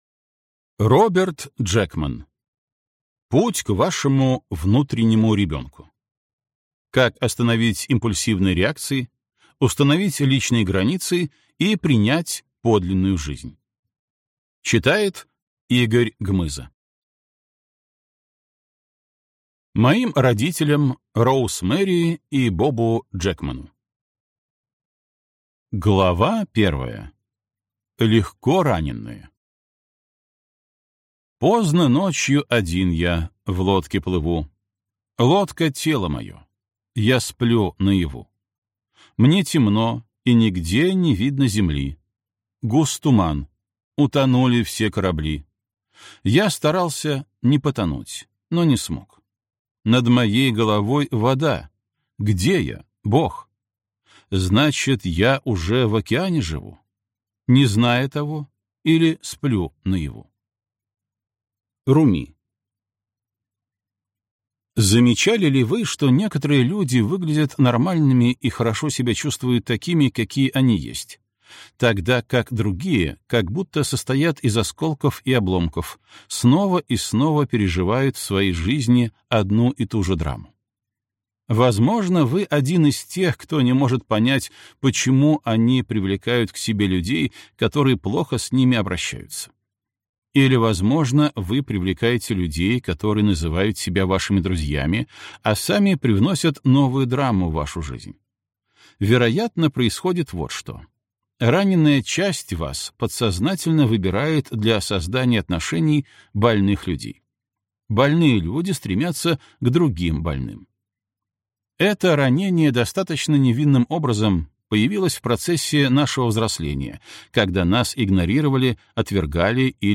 Аудиокнига Путь к вашему внутреннему ребенку. Как остановить импульсивные реакции, установить личные границы и принять подлинную жизнь | Библиотека аудиокниг